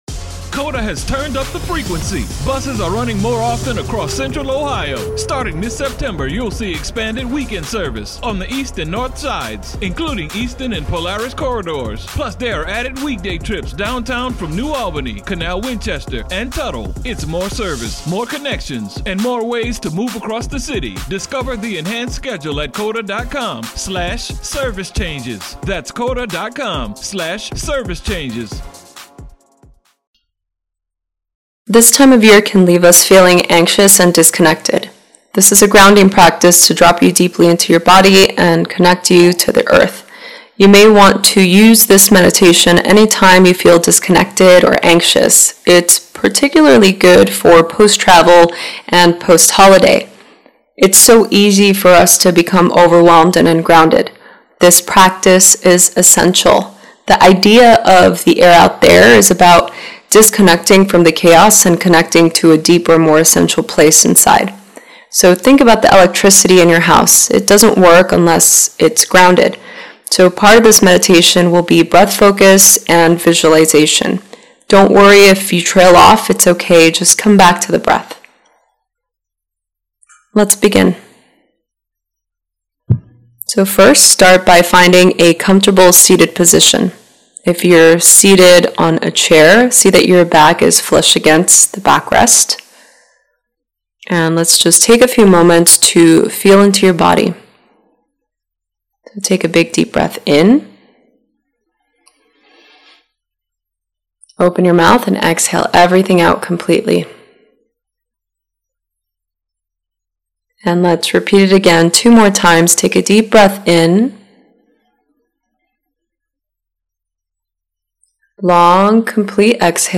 Pranayama for the first 10 minutes then guided meditation for the final 10.